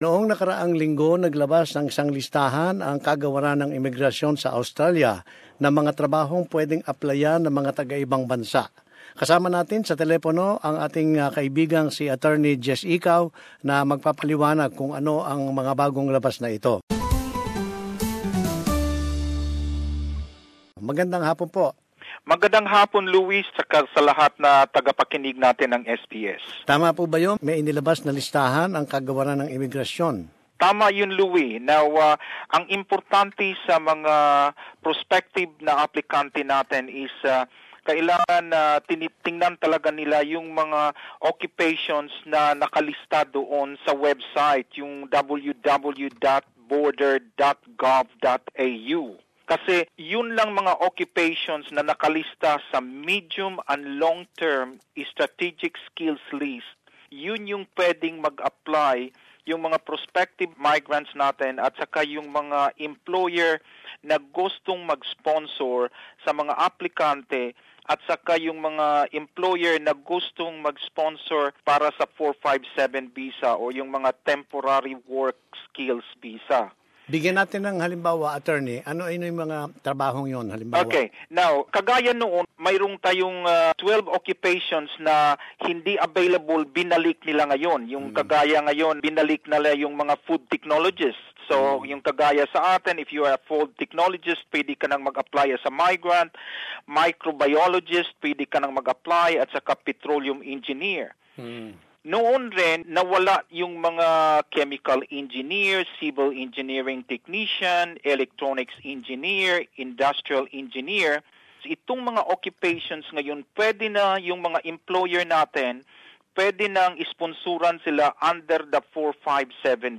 Ipinaliwanag ng abugado ng migrasyon